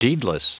Prononciation du mot deedless en anglais (fichier audio)
Prononciation du mot : deedless